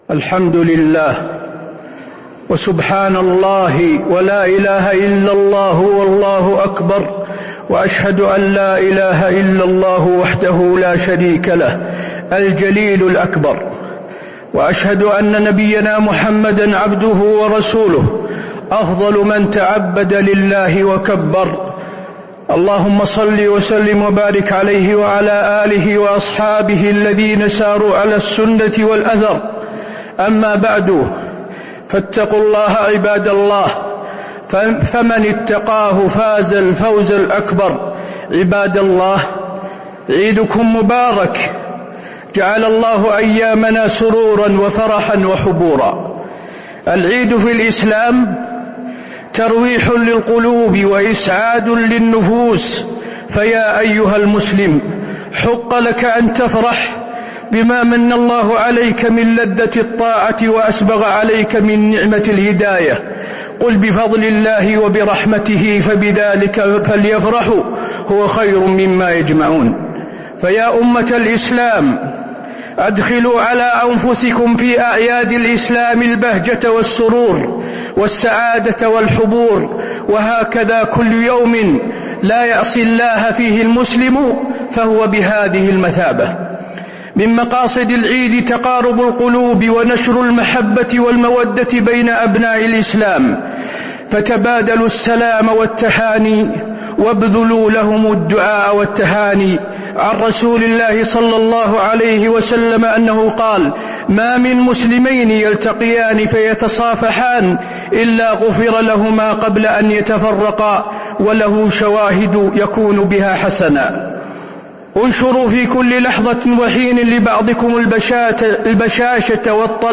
خطبة عيد الأضحى - المدينة - الشيخ حسين آل الشيخ - الموقع الرسمي لرئاسة الشؤون الدينية بالمسجد النبوي والمسجد الحرام
المكان: المسجد النبوي